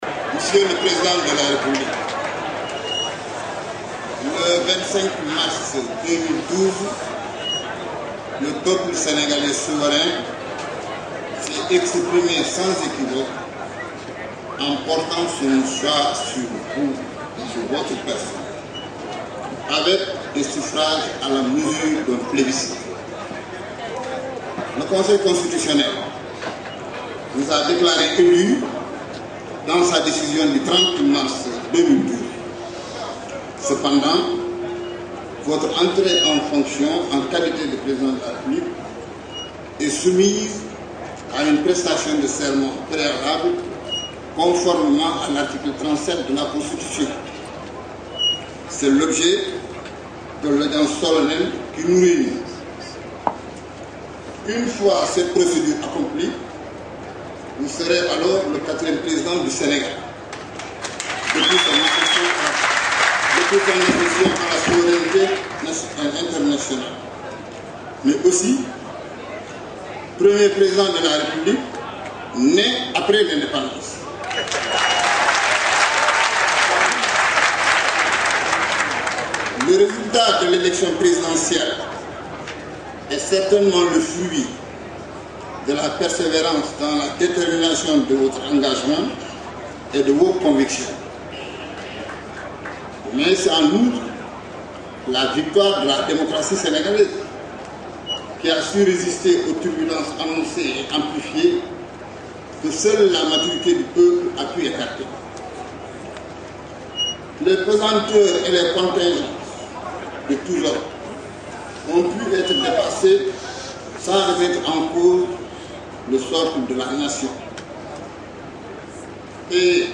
AUDIO – Investiture du Président Macky Sall : Discours du Conseil constitutionnel
On vous propose ici, un extrait du discours du Conseil constitutionnel à l’investiture de Macky Sall.